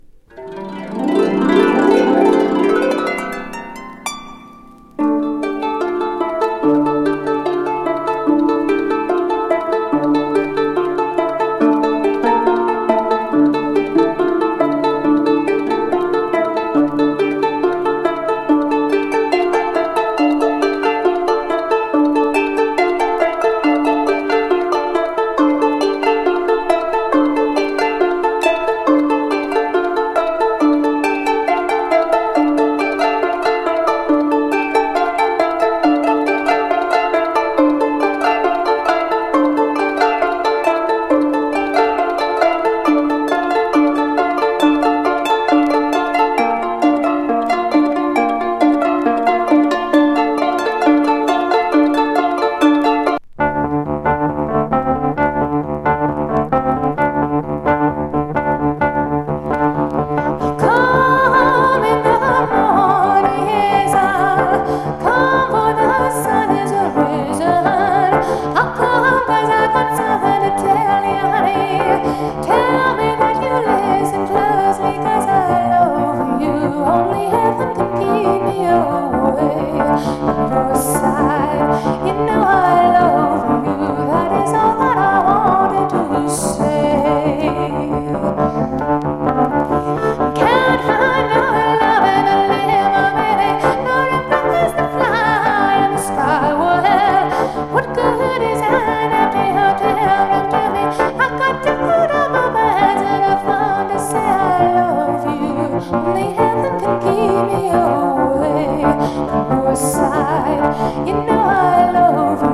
USプライベートプレス・フォーク作。怪しげサイケムード・ハープ・インスト
トラッド超え白昼夢